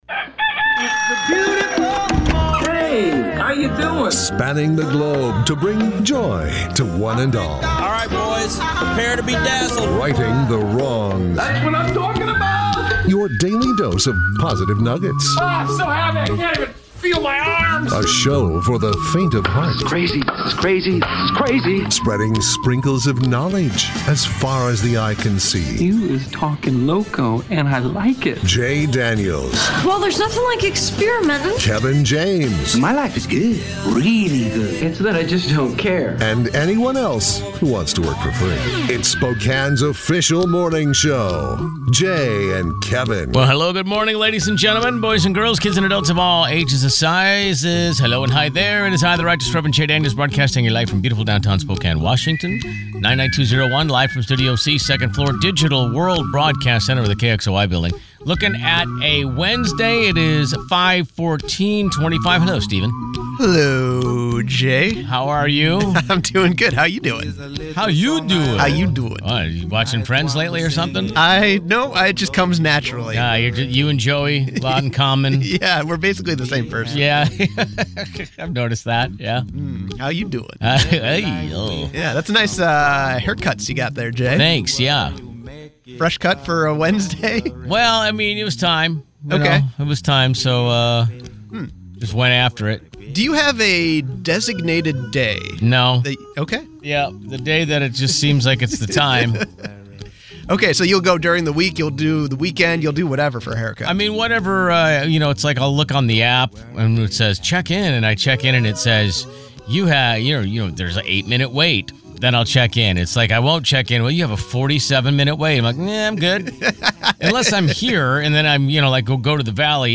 Genres: Comedy